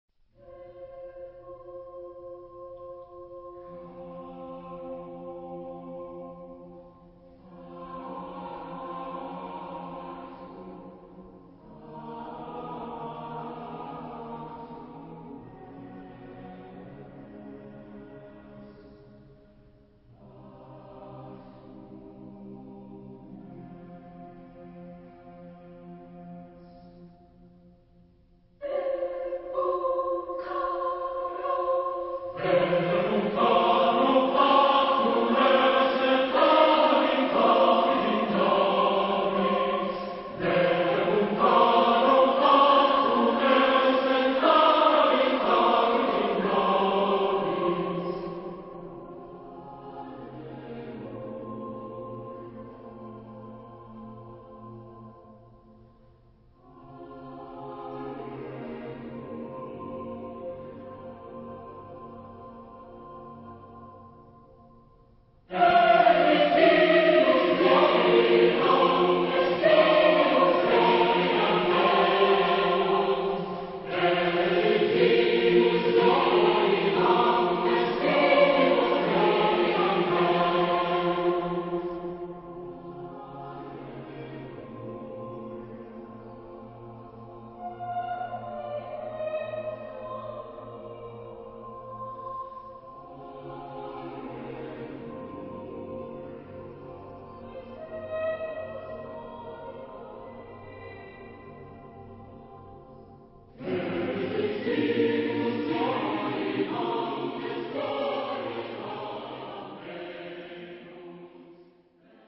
Genre-Style-Forme : Rythmique ; contemporain ; Sacré
Caractère de la pièce : éthéré